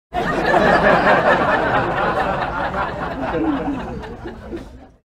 CROWD-LAUGHING.mp3